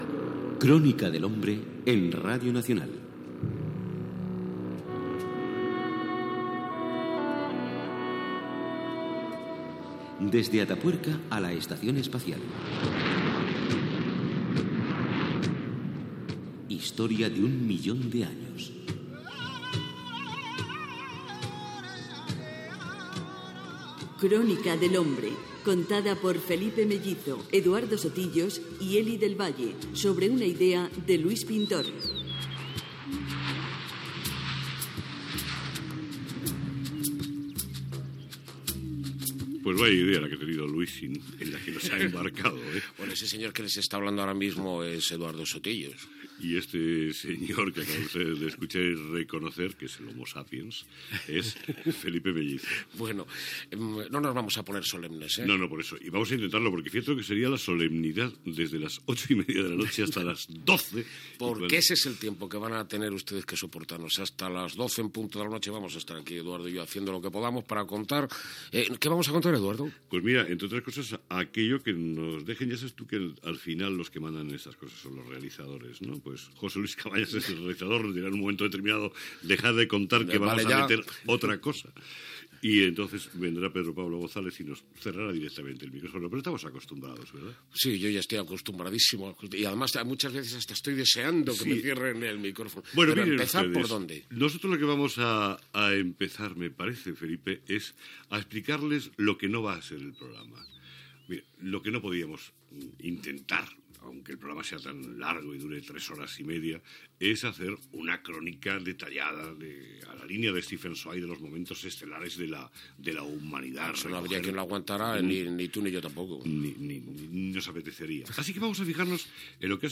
Careta del programa, equip, com serà el programa especial que s'oferirà, lectura d'un poema de Rainer Maria Rilke
Entreteniment